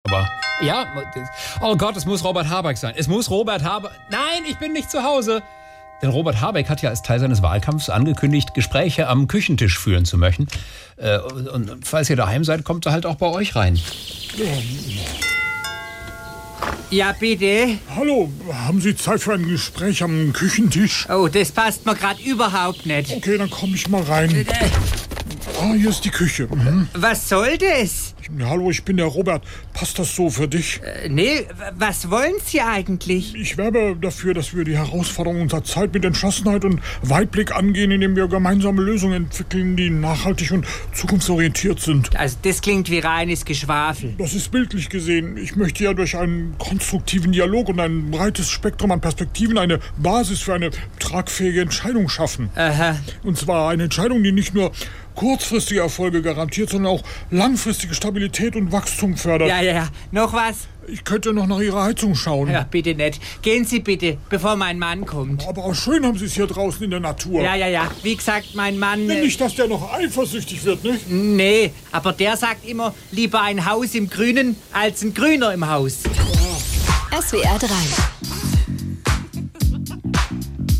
SWR3 Comedy Habeck am Küchentisch